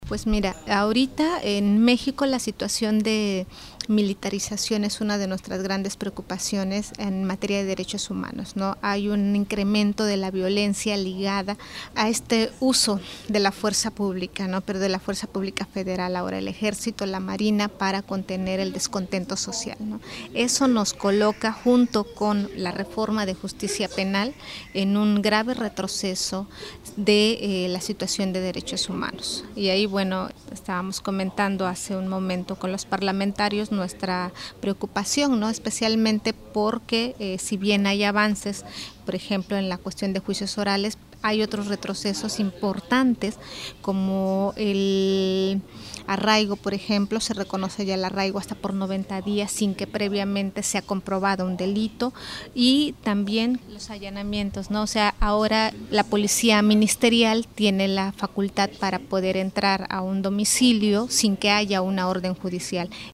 en entrevista con swissinfo.